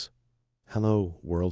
multi-speaker multilingual multilingual-tts text-to-speech
"speaker": "en_male_1"